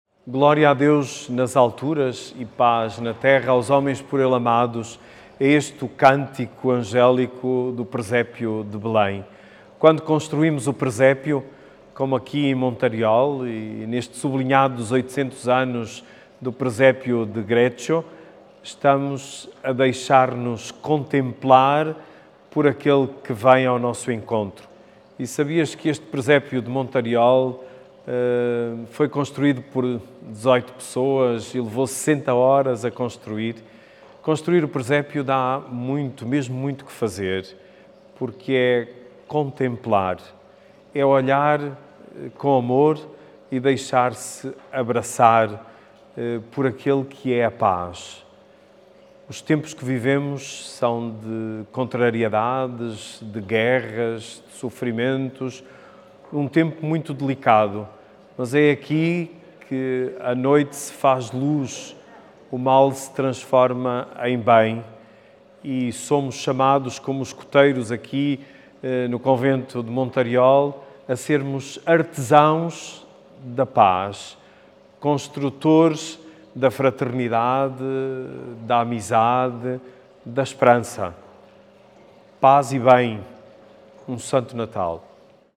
Mensagem de D. José Cordeiro - Arcebispo Metropolita de Braga